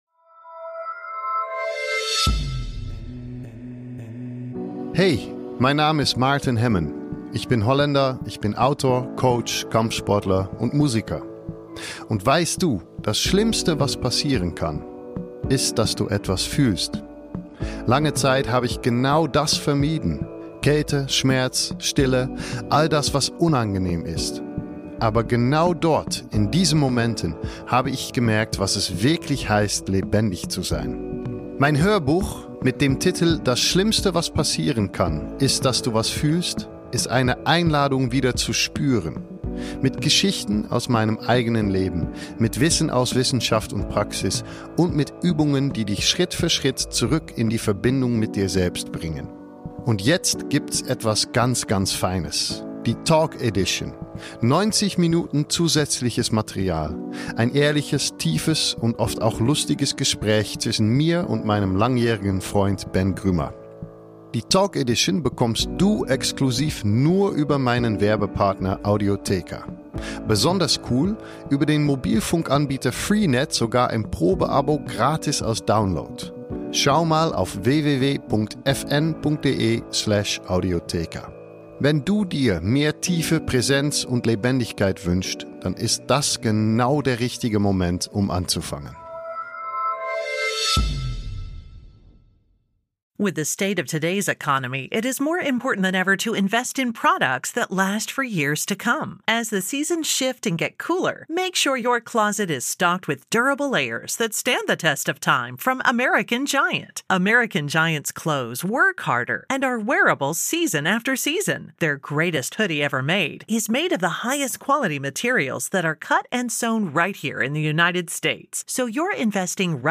Im großen Interview spricht Prof. Dr. Max Otte Klartext – über Crash-Gefahr bei Tech, Gold bei 5.000 Dollar, die Zukunft Deutschlands und seine persönlichen Aktienfavoriten.